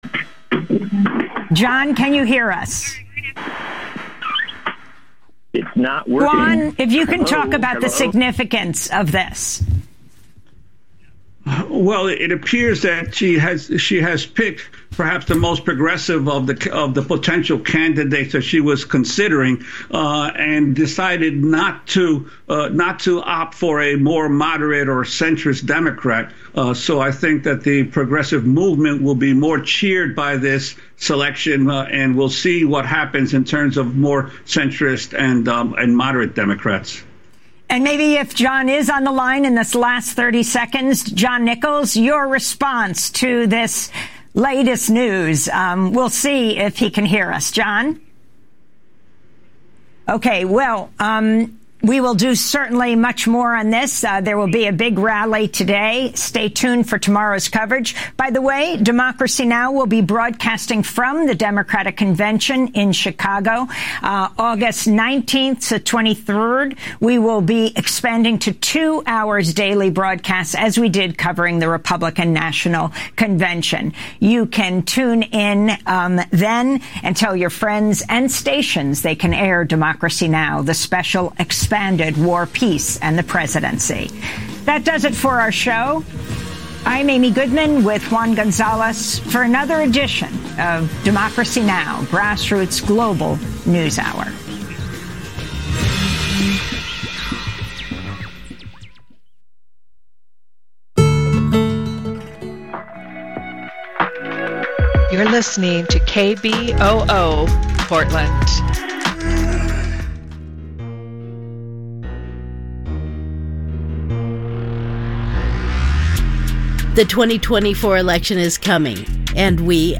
Evening News on 08/06/24